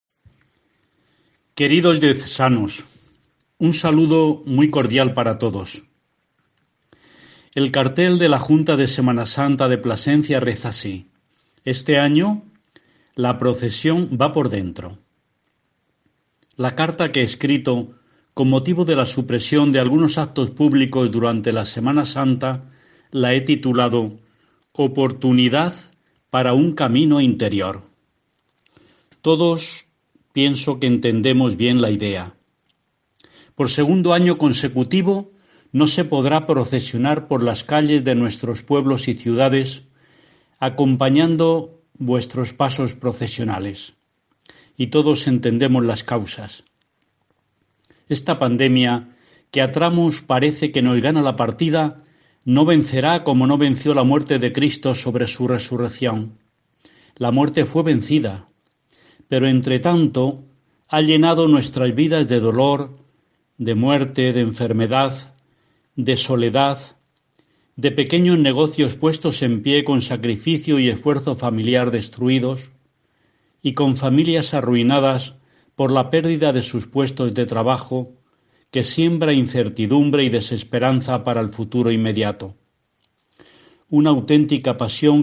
"La procesión va por dentro" Mensaje del Obispo de Plasencia a todos los diocesanos